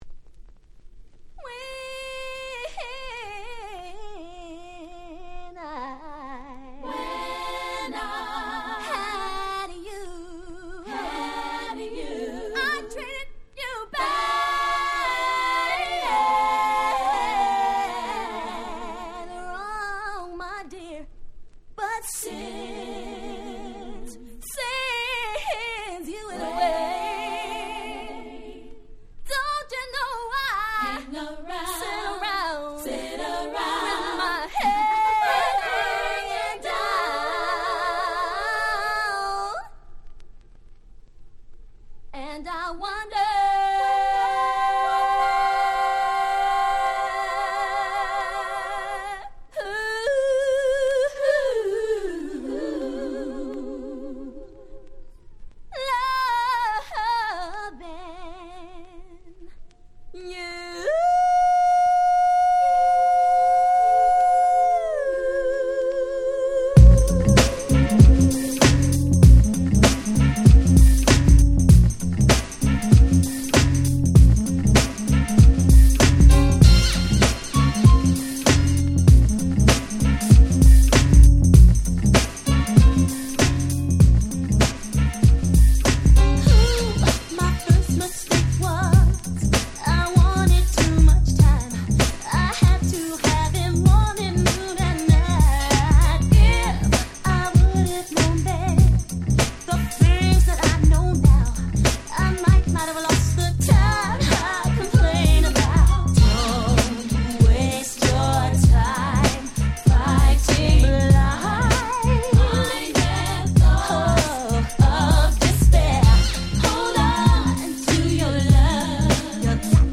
※一部試聴ファイルは別の盤から録音してございます。
問答無用の90's R&B Classics !!
90's キャッチー系